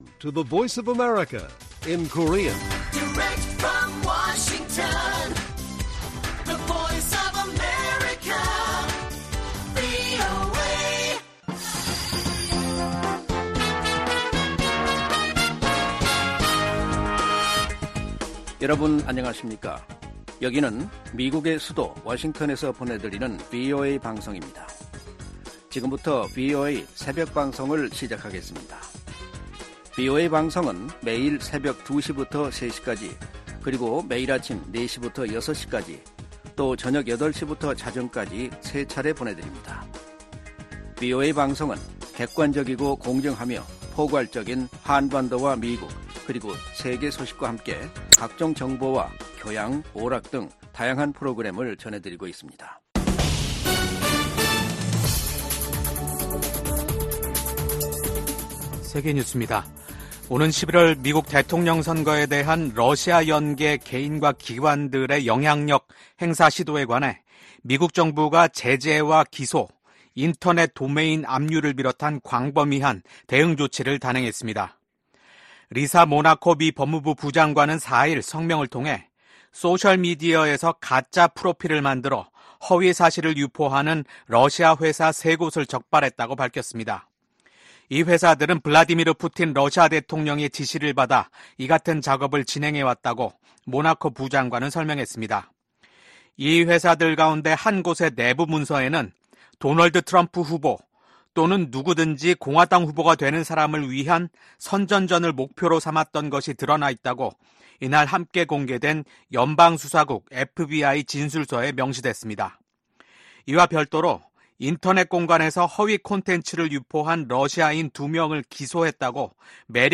VOA 한국어 '출발 뉴스 쇼', 2024년 9월 6일 방송입니다. 미국과 한국이 북한의 도발에 대한 강력한 대응 의지를 재확인했습니다. 북한이 25일만에 또 다시 한국을 향해 쓰레기 풍선을 날려 보냈습니다. 국제 핵실험 반대의 날을 맞아 북한의 핵과 미사일 개발을 규탄하는 목소리가 이어졌습니다.